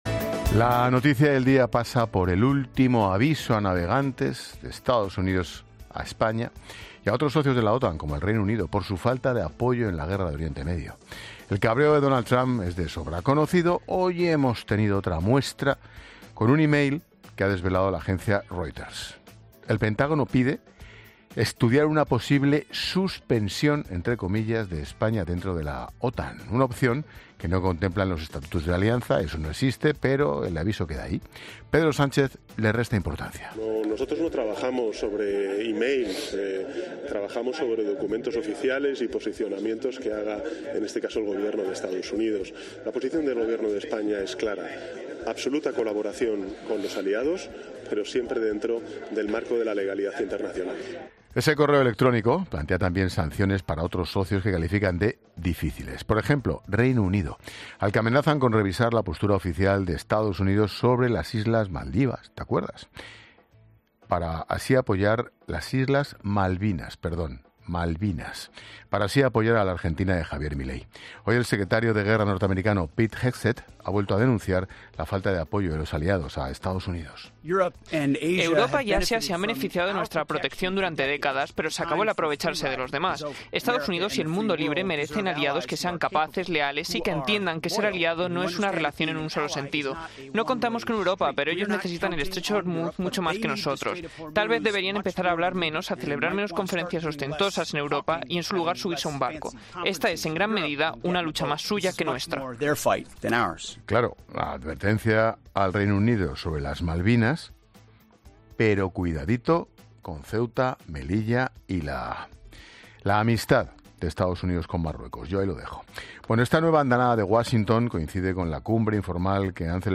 Ángel Expósito valora la advertencia de EEUU a España y otros socios de la OTAN